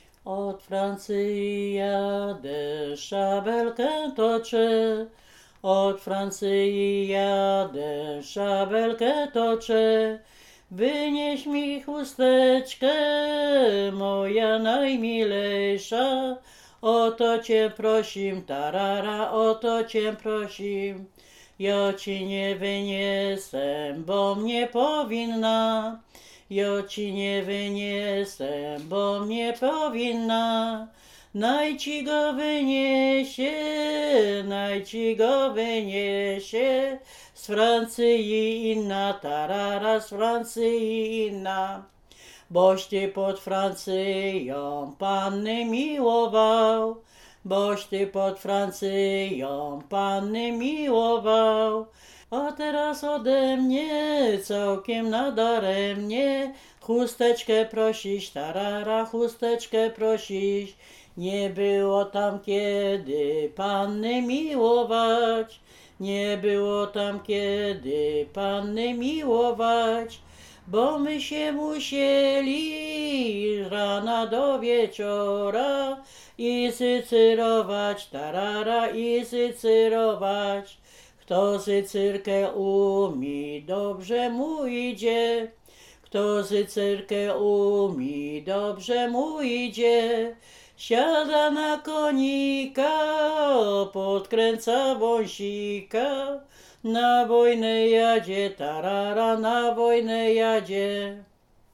województwo dolnośląskie, Powiat lwówecki, gmina Lwówek Śląski, wieś Zbylutów
liryczne wojenkowe rekruckie